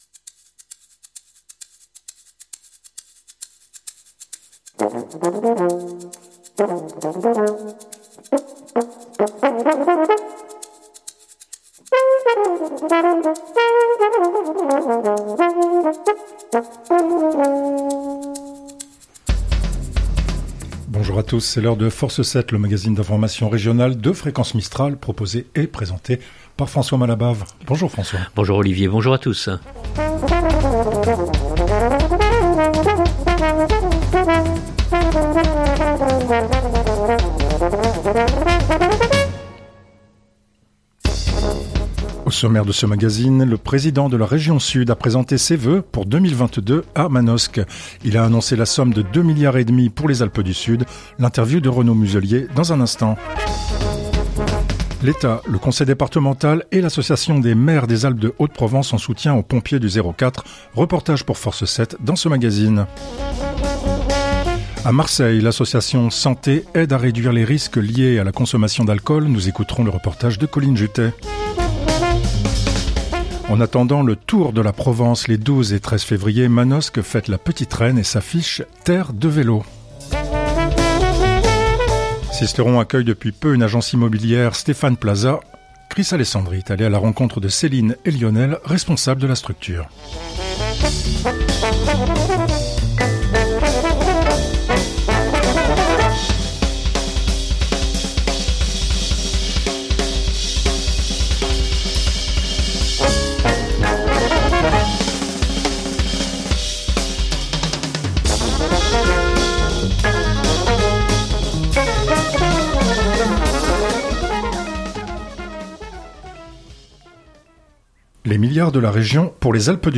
Il a annoncé la somme de deux milliards et demi pour les Alpes du Sud. L’interview de Renaud Muselier.
Reportage pour Force 7 dans ce magazine.